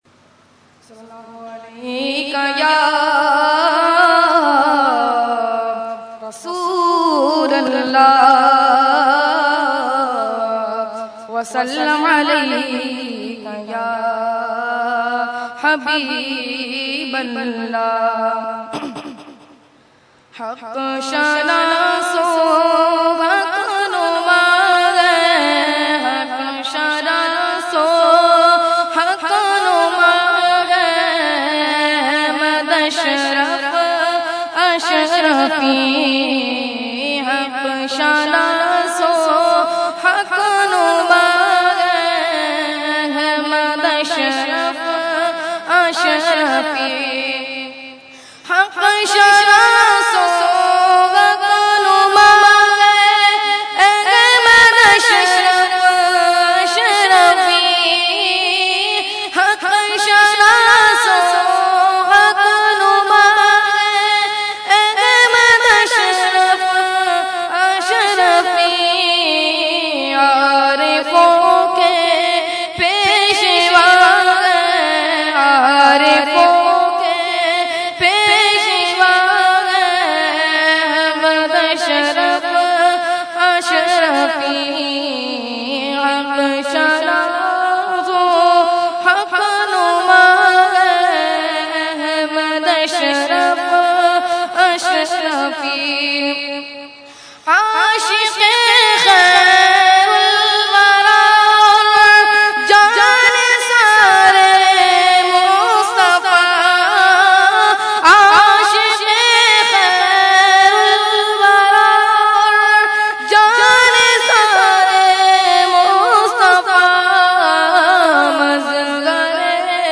Category : Manqabat | Language : UrduEvent : Urs Ashraful Mashaikh 2014